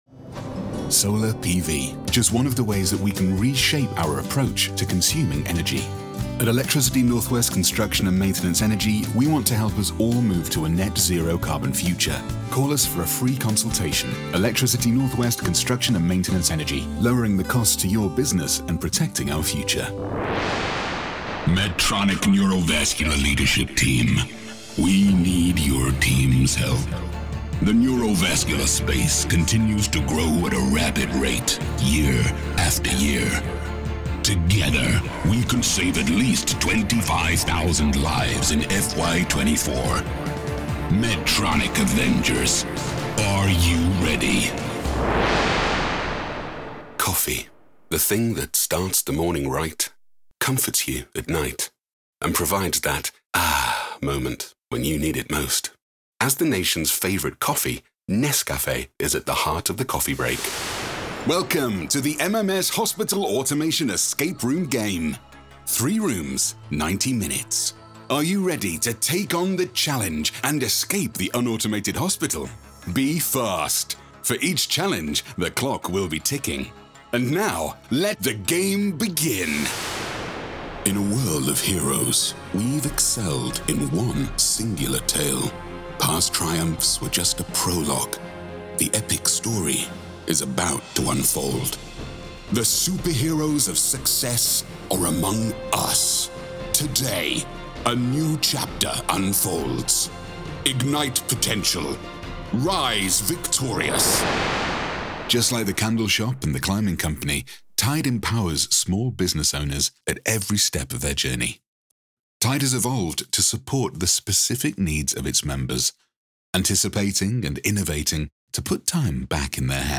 From Northern accent to well spoken RP and everything in between
Corporate
VOICE-REEL-CORPORATE-17.9.25.mp3